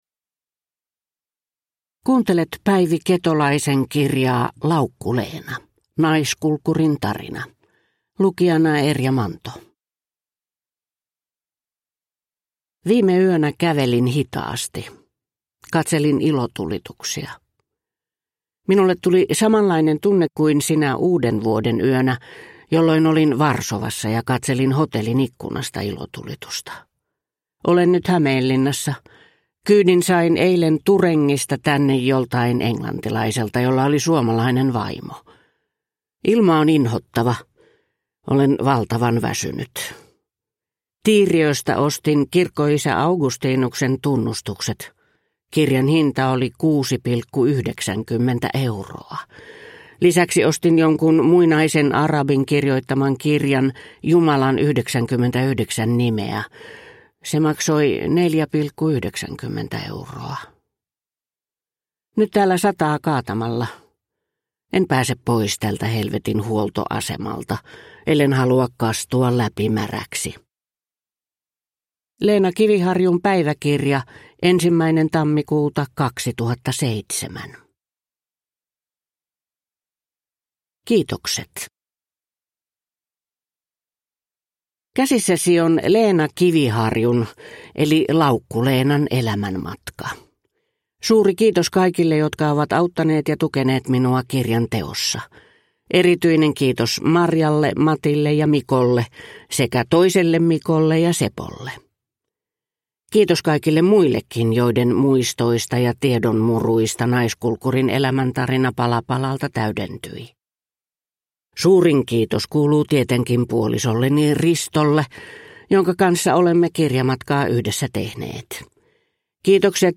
Laukku-Leena (ljudbok) av Päivi Ketolainen